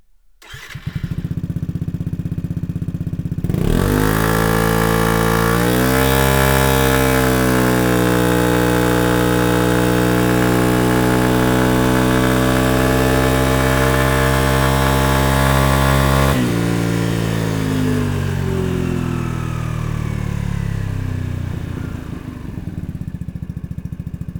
Mit dem Akrapovic Slip-On System bekommt Ihr Scooter diesen unverkennbaren tiefen und satten Akrapovic Sound, und auch das optische Erscheinungsbild ändert sich dramatisch.
Sound Akrapovic Slip-On